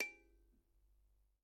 Thumb piano » thumbpiano lowC 2
描述：An African thumb piano (kalimba) with a tomato can as a resonator.
Recorded using a RODE NT3 microphone and an ART Tube MP preamp.
标签： 24bit kalimba mono studiorecording thumbpiano unprocessed